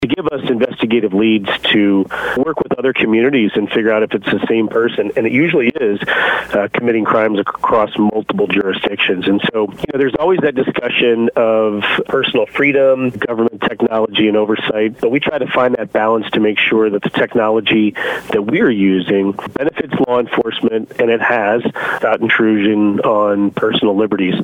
Jefferson County Sheriff Dave Marshak says the cameras are also called license plate readers and have helped track certain crimes to certain vehicles.